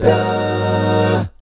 1-ABMI7 AA-R.wav